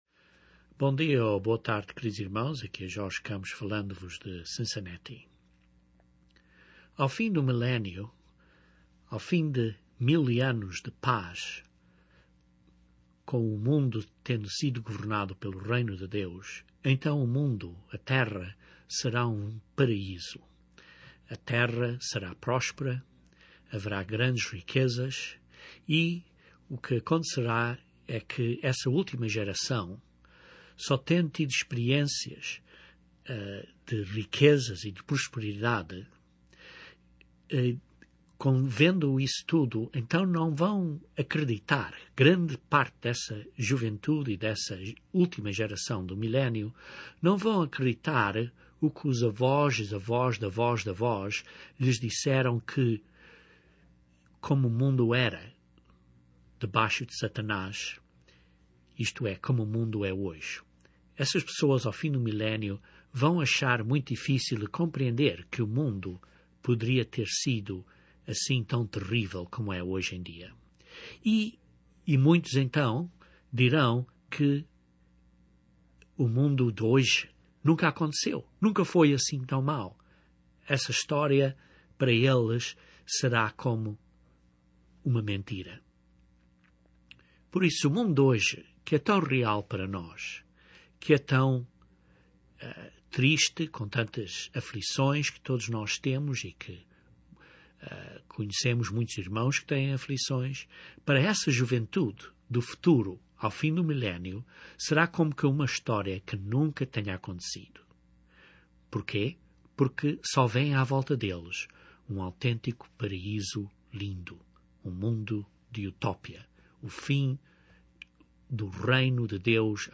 É altura da ressurreição para o julgamento, a segunda ressurreição. Este sermão prova da bíblia este periodo de grande esperança para os mortos.